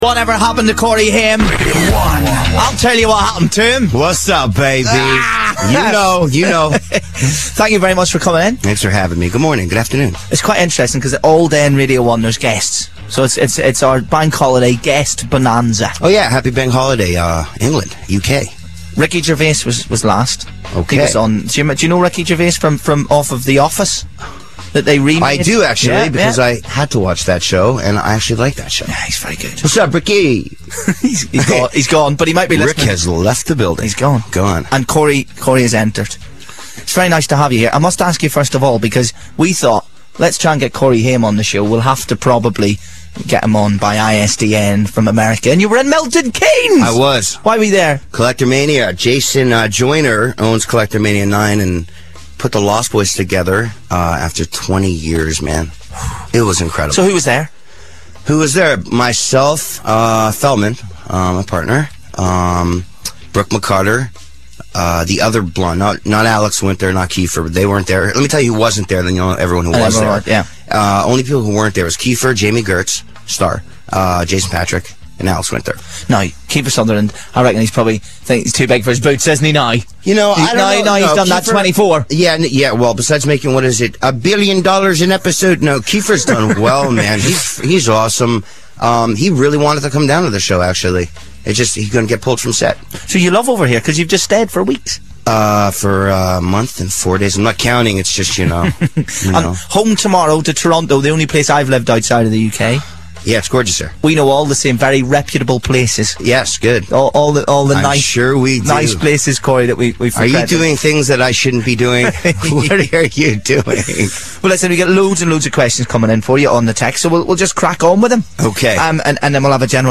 Corey Haim: RADIO 1 Interview